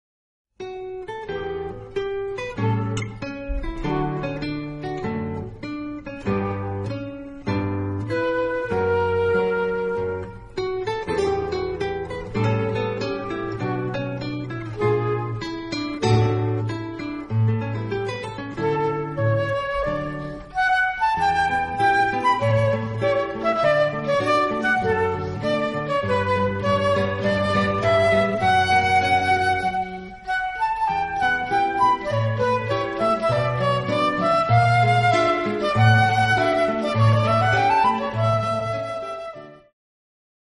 guitar Two seminal pieces for guitar and flute duet.